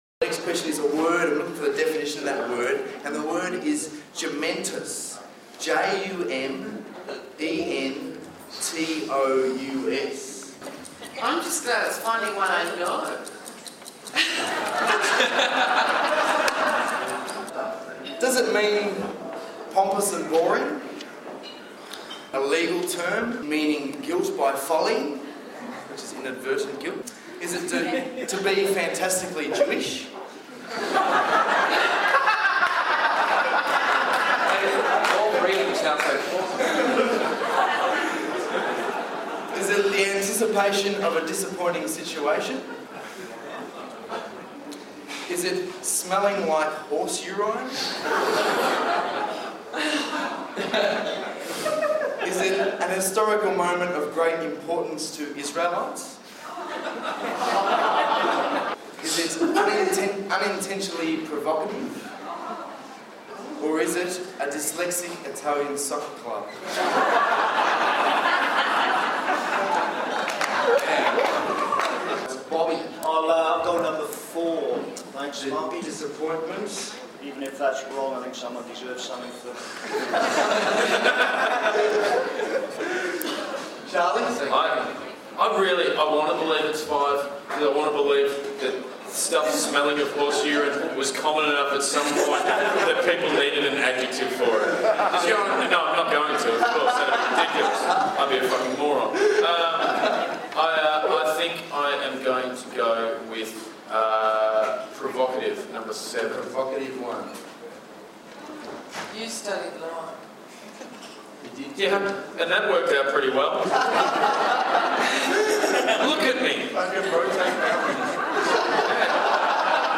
A new game show of bluff and deception,
The Melbourne Comedy Festival Spirit